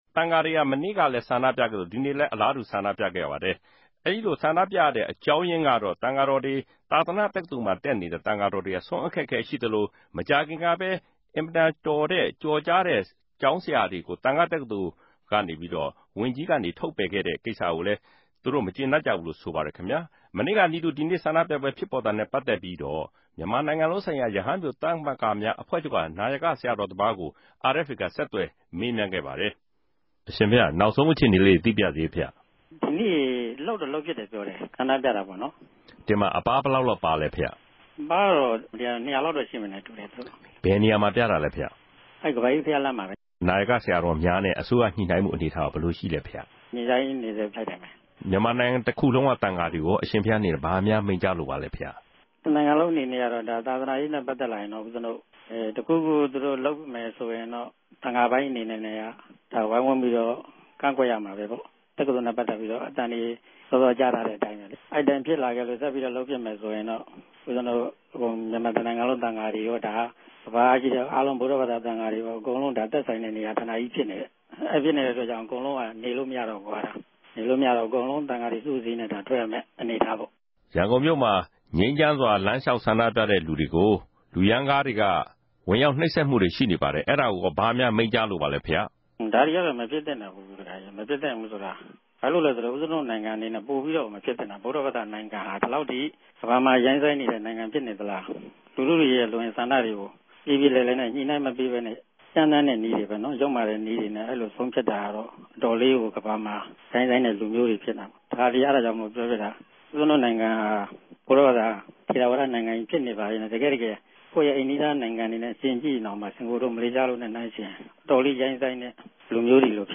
ဒီကနေႛ ဆ္ဋိံူပပြဲနဲႛပတ်သက်္ဘပီးတော့ ူမန်မာိံိုင်ငံလုံးဆိုင်ရာ ရဟန်းပဵြိသမဂ္ဂမဵားအဖြဲႚခဵြပ်က နာယက ဆရာတော်တပၝးကို RFAက ဆက်သြယ်မေးူမန်းခဲ့ရာမြာ အဓိကအခဵက်တေအြနေနဲႛ